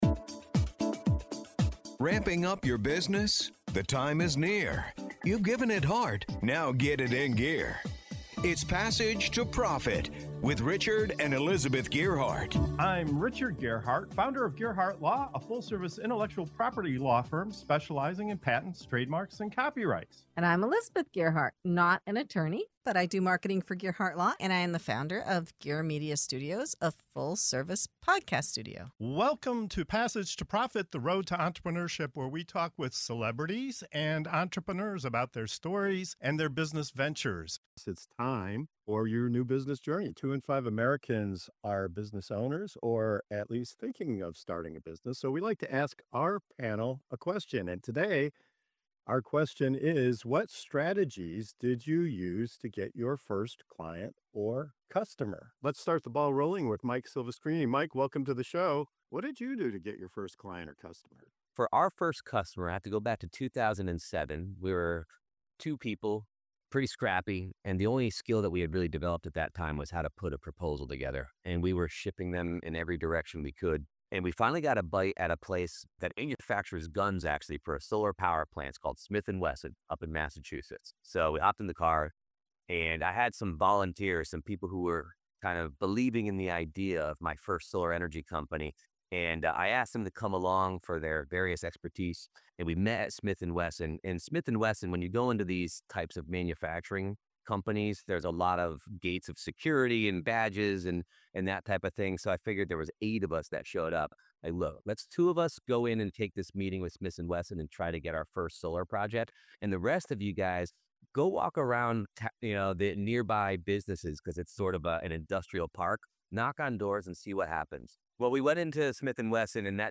This segment of "Your New Business Journey" on Passage to Profit Show dives into the real stories of how entrepreneurs landed their very first clients—from cold calls and door-knocking, to chamber of commerce events, to experimenting with technology that set them apart. You’ll hear how persistence, creativity, and good old-fashioned human connection helped founders across industries turn ideas into thriving businesses.